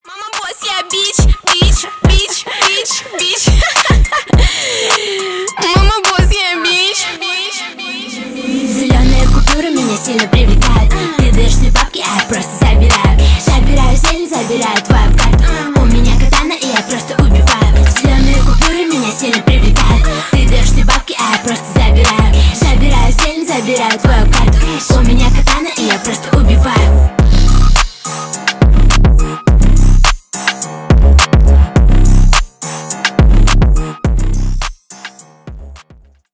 Внимание Ненормативная лексика!
Рэп и Хип Хоп